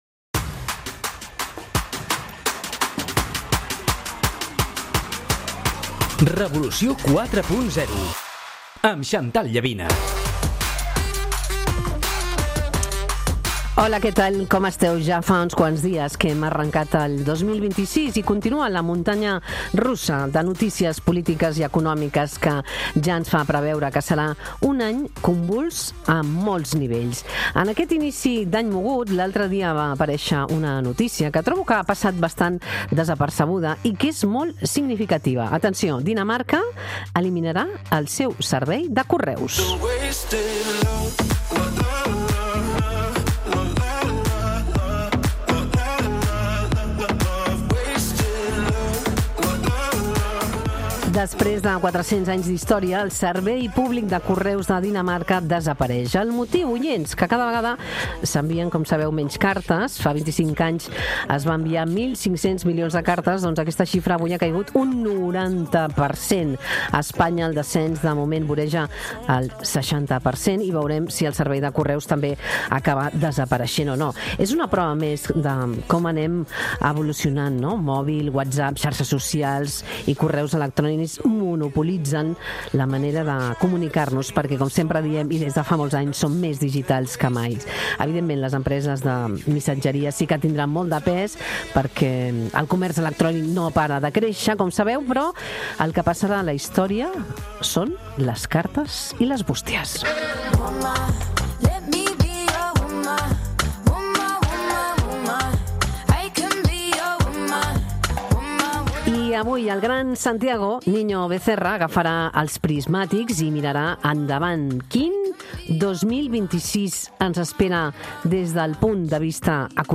Assistim a la primera masterclass de l'any de l'economista Santiago Niño-Becerra, que analitza el 2026 com un any de transició i d'inici del període de la intel·ligència artificial. La conversa aborda el context internacional, amb especial atenció als Estats Units, Veneçuela i la creixent divisió del món en blocs d'influència.